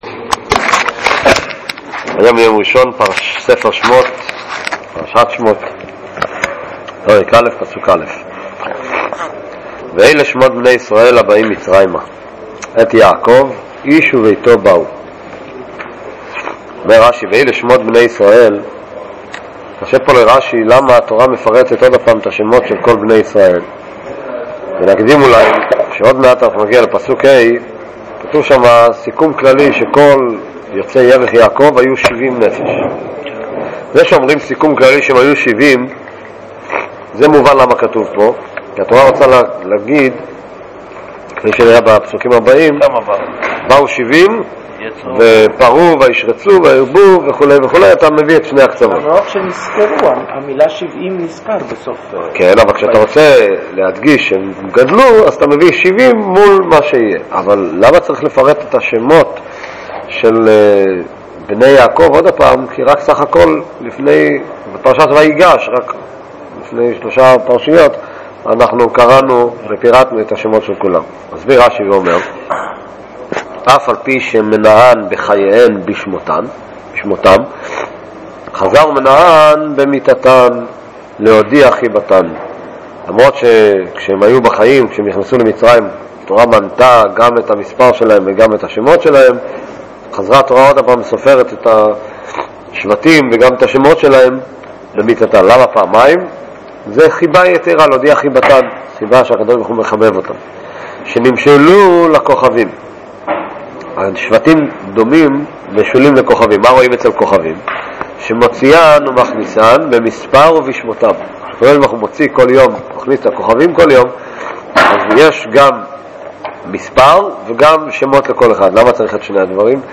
ל-COL נמסר כי כל שיעור מוקלט בקובץ MP3 ואורכו כ- 25 דק' (השיעורים של השבתות נלמדו והוקלטו בימי שישי). COL מגיש שלושה שיעורים לדוגמא על פרשת שמות.